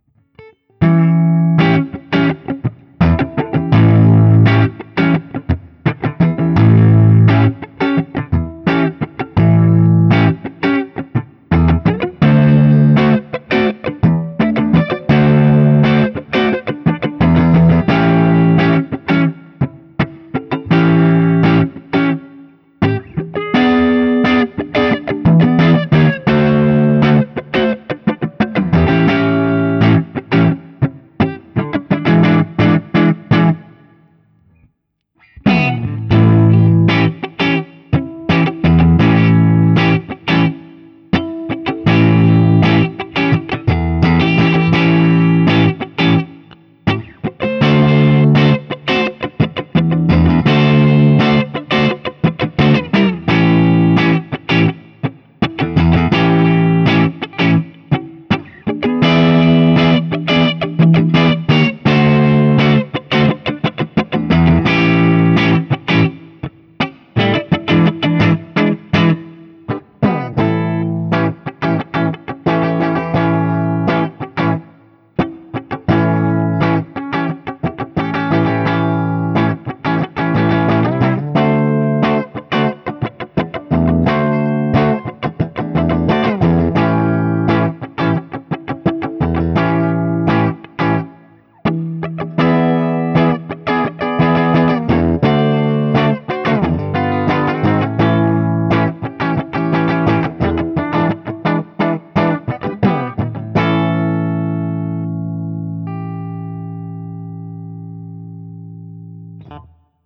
7th Chords
As usual, for these recordings I used my normal Axe-FX III  setup through the QSC K12 speaker recorded direct into my Mac Pro using Audacity.
For each recording I cycle through the neck pickup, both pickups, and finally the bridge pickup.
Guild-Nightbird-DX-ODS100-7th.wav